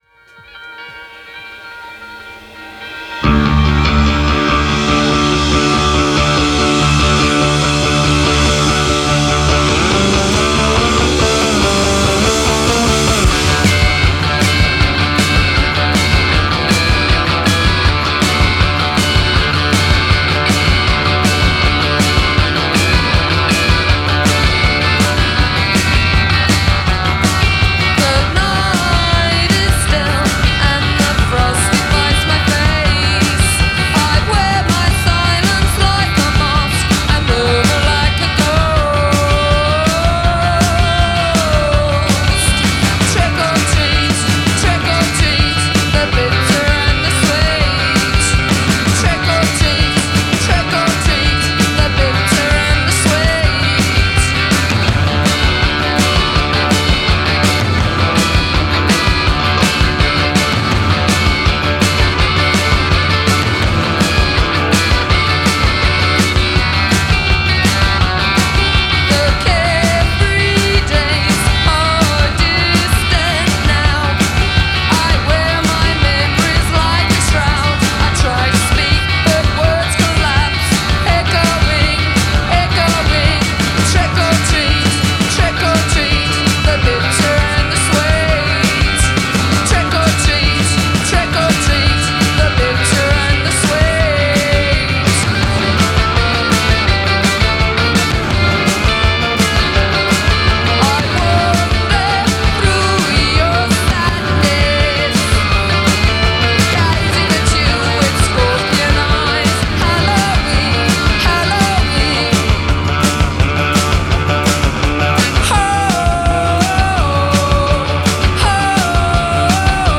English punk rock band